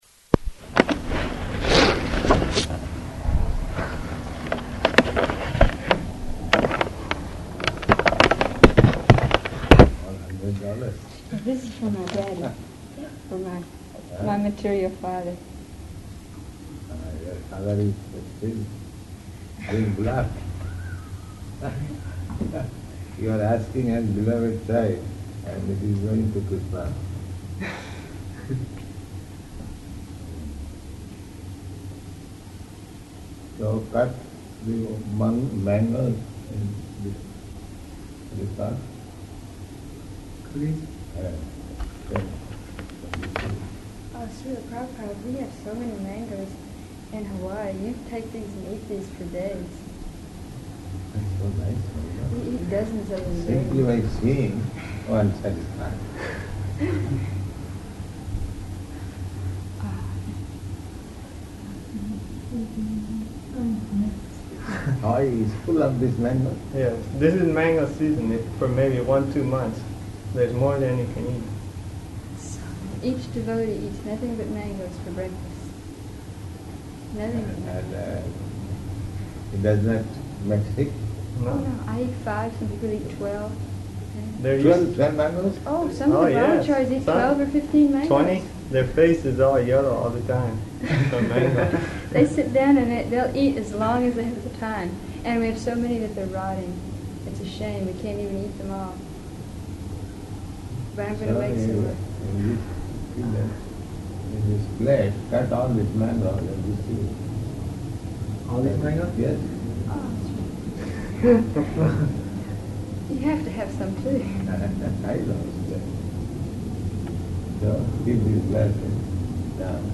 -- Type: Conversation Dated: July 1st 1971 Location: Los Angeles Audio file